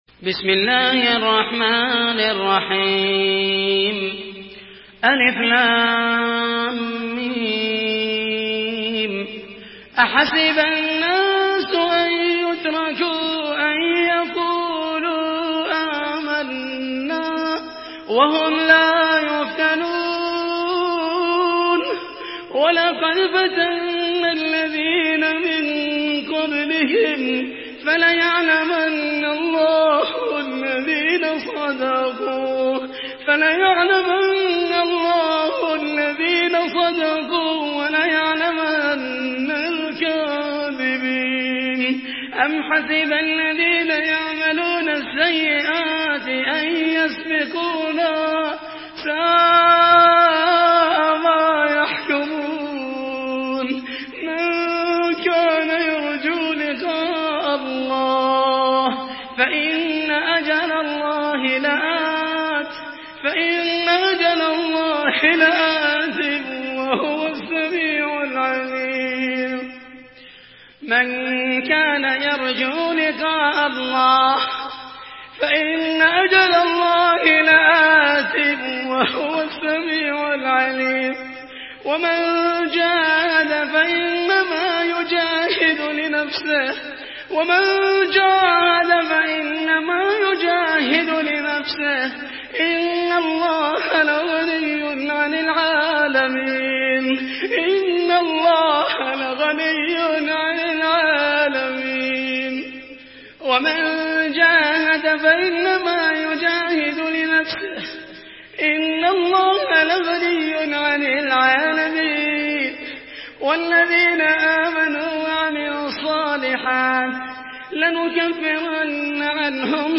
Surah Al-Ankabut MP3 in the Voice of Muhammed al Mohaisany in Hafs Narration
Listen and download the full recitation in MP3 format via direct and fast links in multiple qualities to your mobile phone.
Murattal